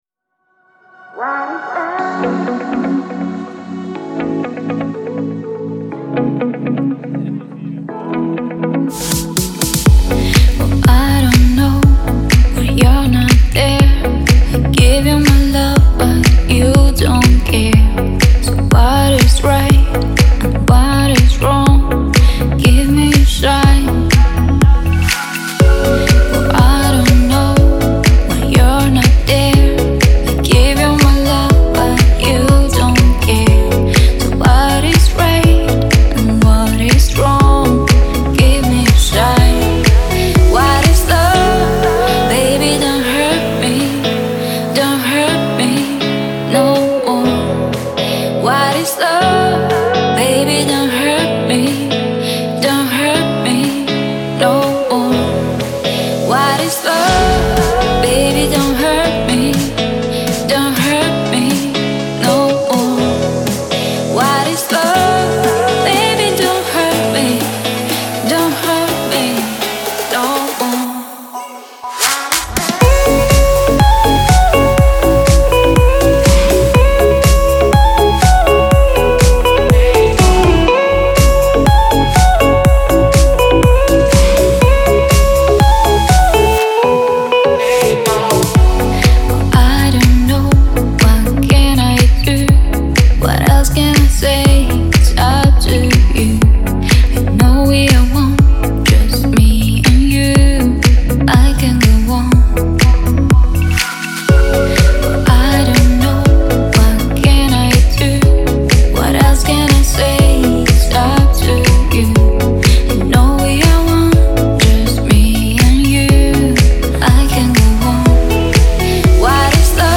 это динамичная танцевальная композиция в жанре EDM